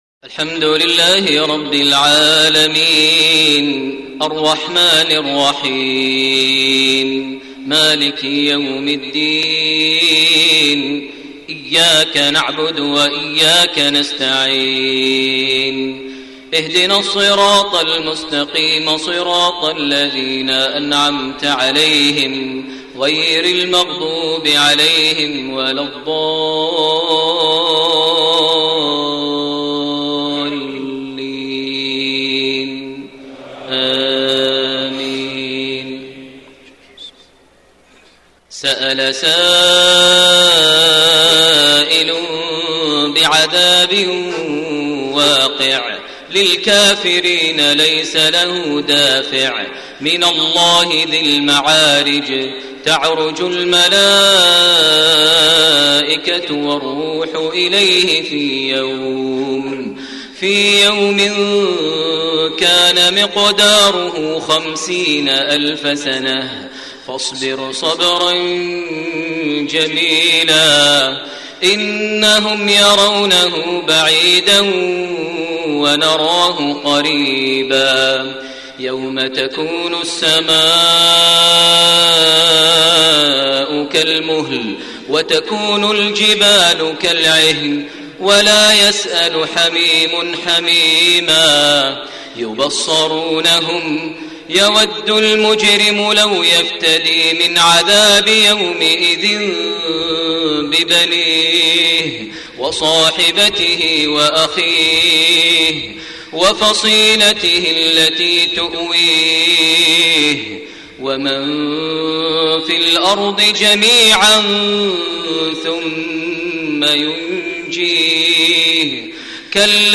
صلاة المغرب 9 ربيع الثاني 1433هـ فواتح سورة المعارج 1-35 > 1433 هـ > الفروض - تلاوات ماهر المعيقلي